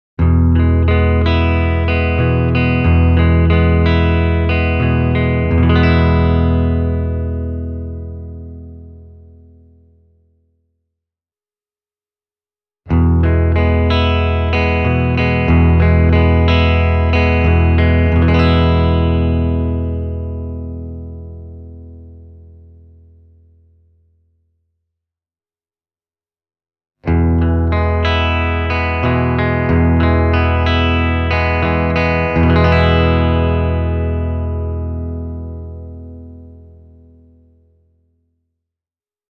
…and a 1970s Japanese ”lawsuit” copy of a Gibson ES-335: